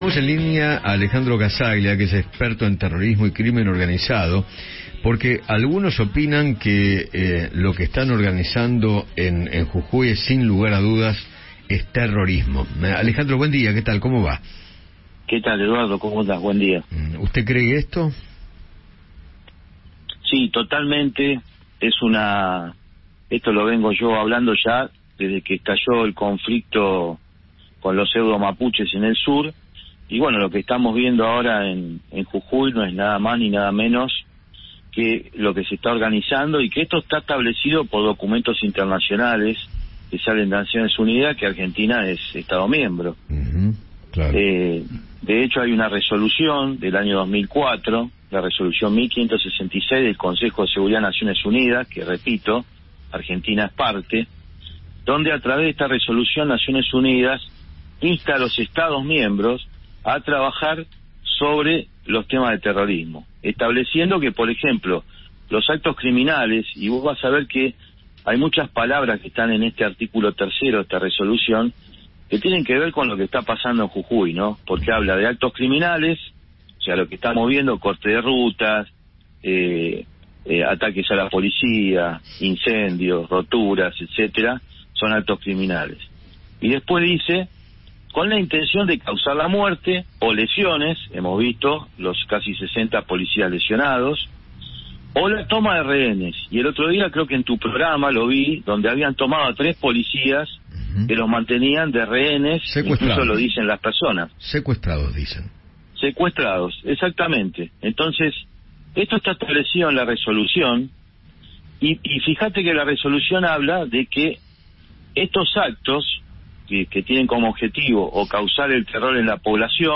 dialogó con Eduardo Feinmann sobre las violentas manifestaciones en Jujuy.